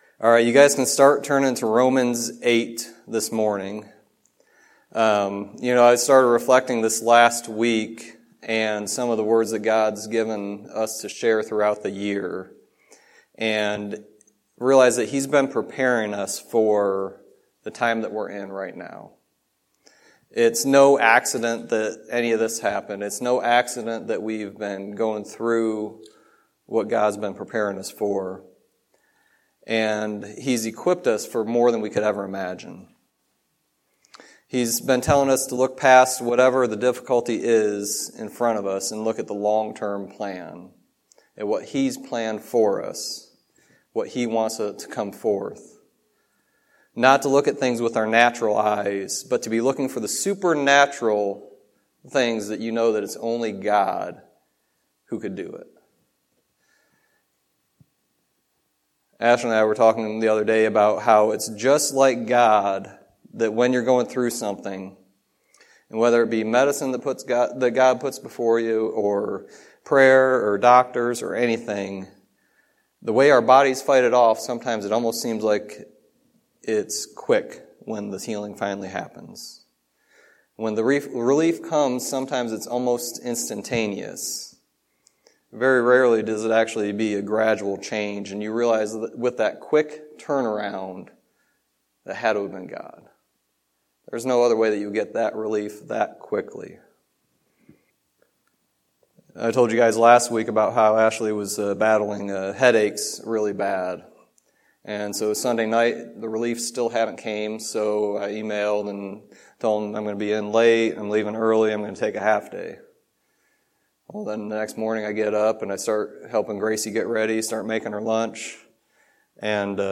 Sermon messages available online.
Romans 8:18-25 Service Type: Sunday Teaching There is hope in the waiting.